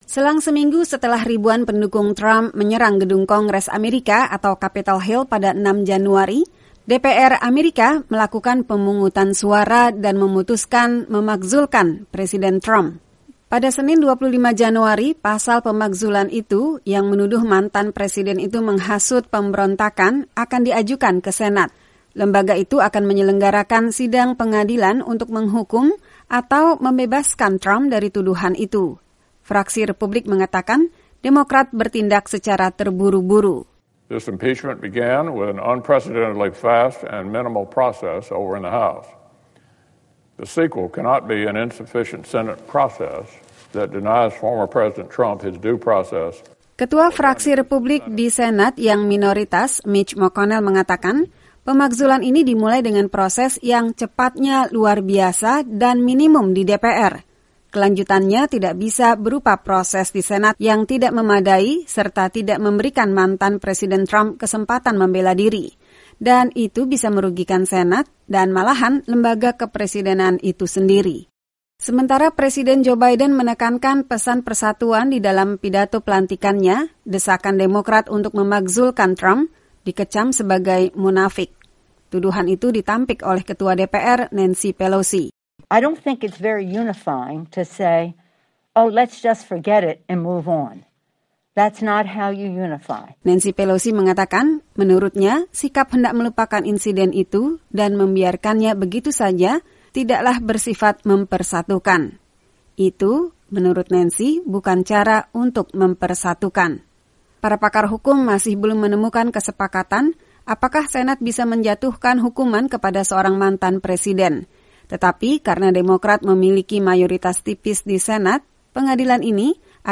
DPR AS siap mengirim pasal pemakzulan terhadap Trump kepada Senat pada Senin. Isi pasal tersebut, menuduh mantan presiden itu menghasut pemberontakan, yang berakibat massa menyerang Gedung Capitol pada 6 Januari. Laporan berikut disiapkan tim VOA.